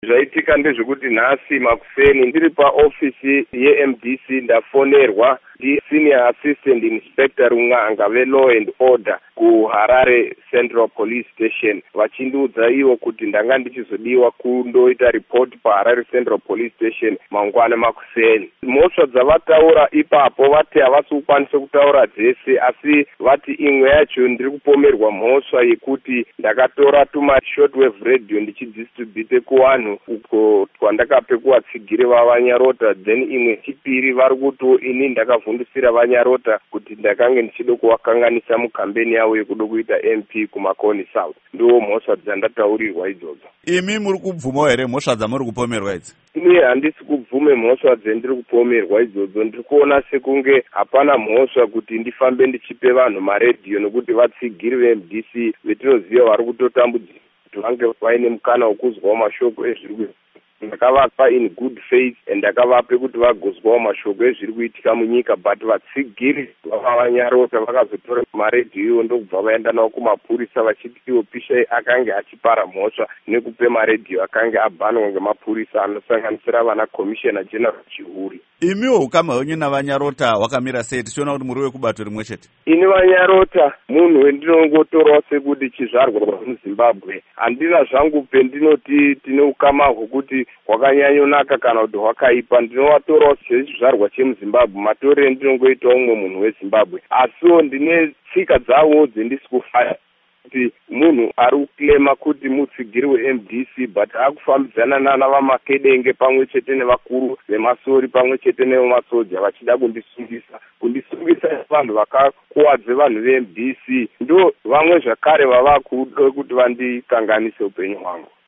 Hurukuro naVaPishai Muchauraya